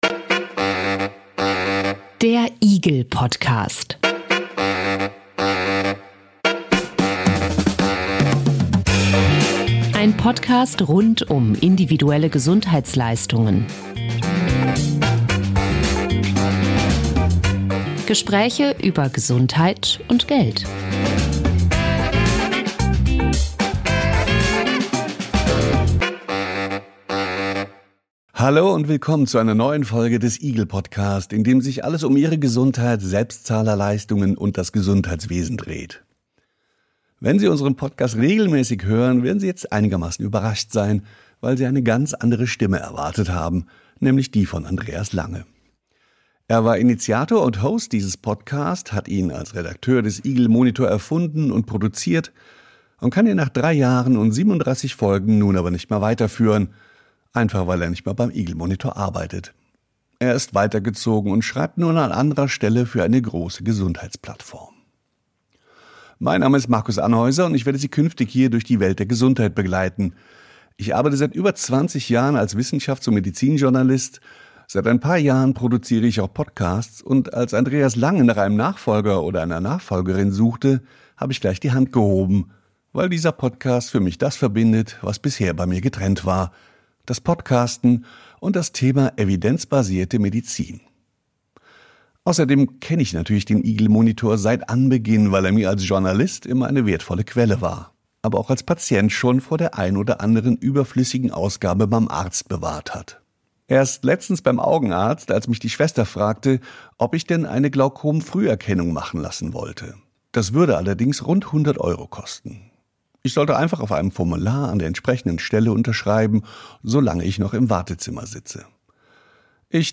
Wir suchen den Dialog mit gesundheitspolitischen Akteuren, holen sie an den Tisch, diskutieren über Geld, Medizin, Rechte von Patientinnen und Patienten.